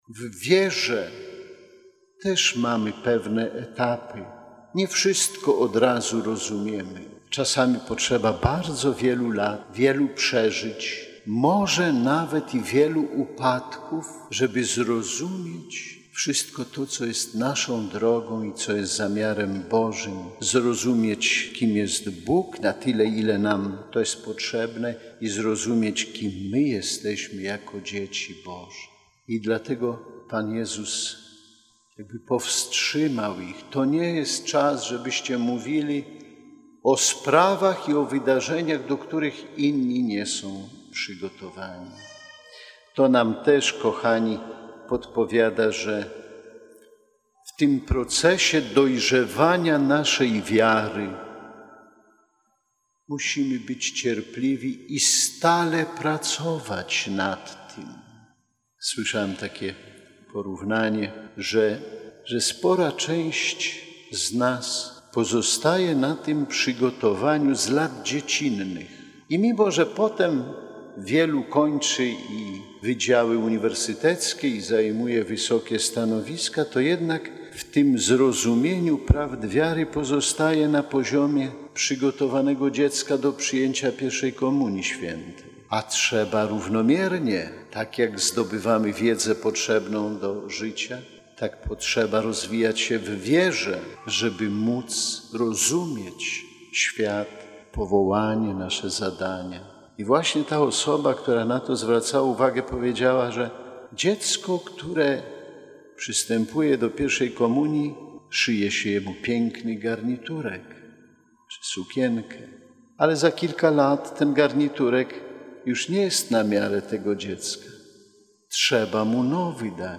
W II niedzielę Wielkiego Postu, biskup warszawsko-praski przewodniczył mszy św. w bazylice katedralnej św. Michała Archanioła i św. Floriana Męczennika.
Przywołując w homilii przykład Abrahama, biskup Kamiński podkreślił, że powołanie jest łaską, która domaga się od człowieka odpowiedzi.